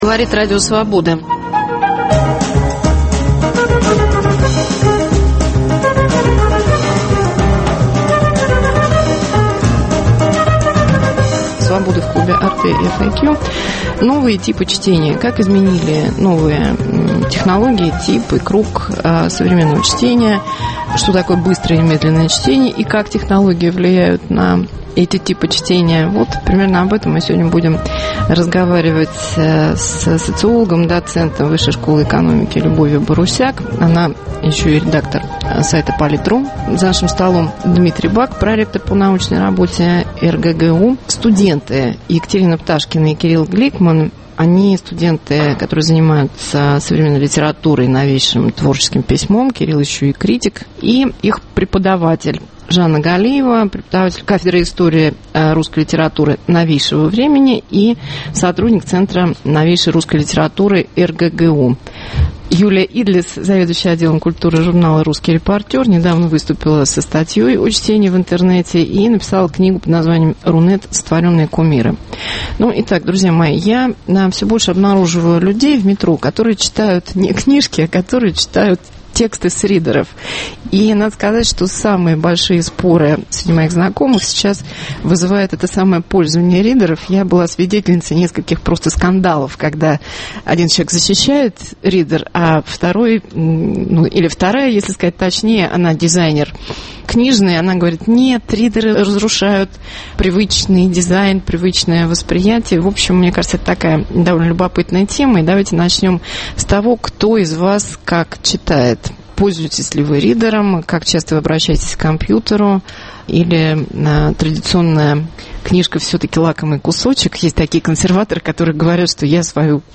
Свобода в клубе АrteFAQ.